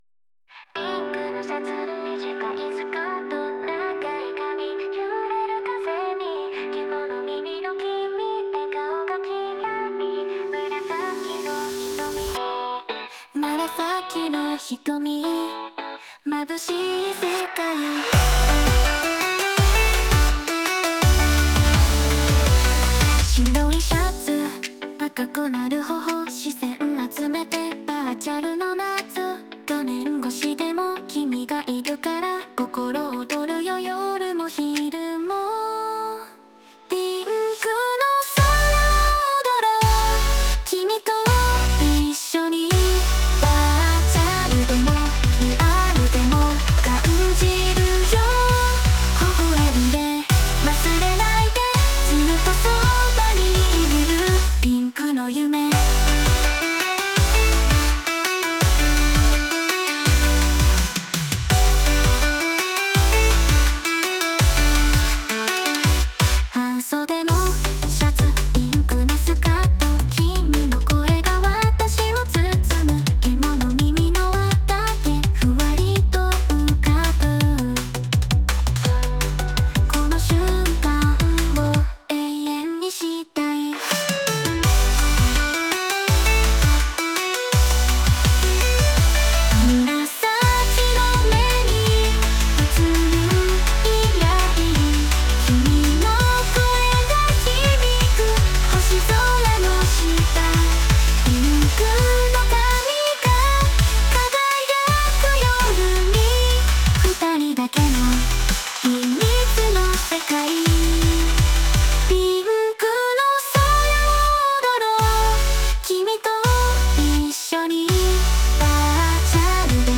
Music EDM Music